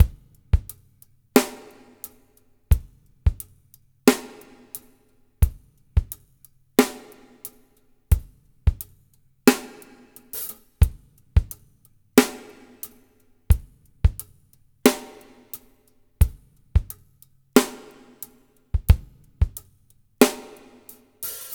LOOSE DRMS-L.wav